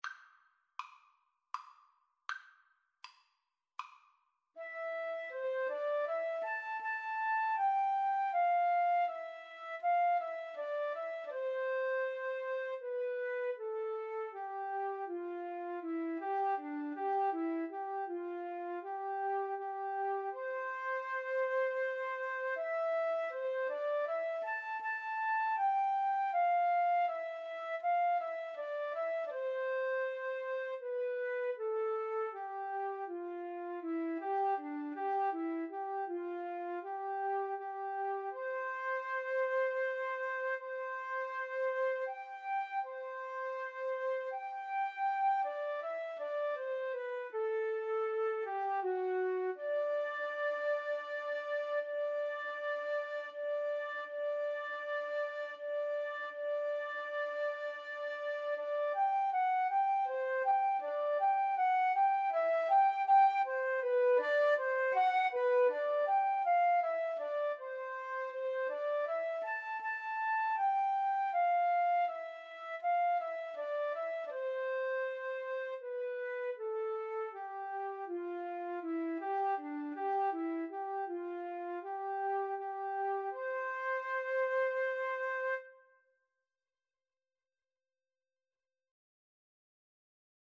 Flute 1Flute 2
Andante
3/4 (View more 3/4 Music)
Classical (View more Classical Flute Duet Music)